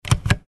Вы найдете различные варианты щелчков и клацанья выключателя: от классических резких до современных мягких.
Щелчок включения настольной лампы